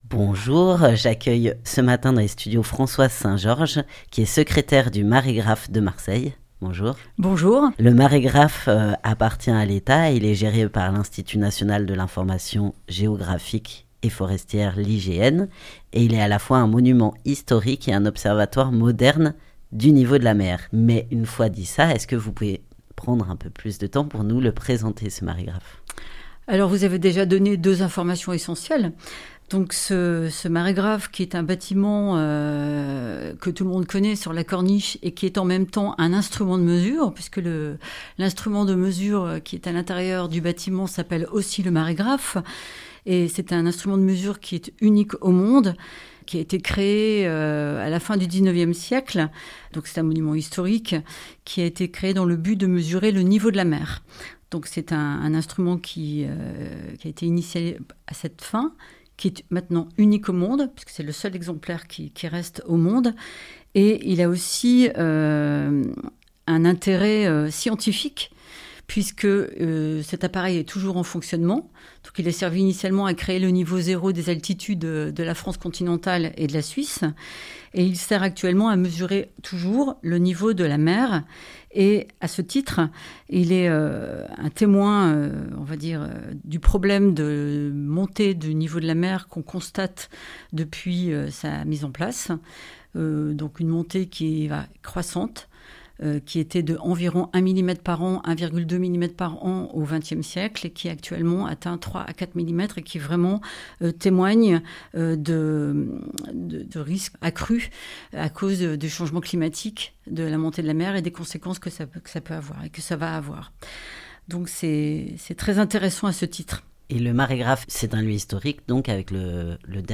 dans les studios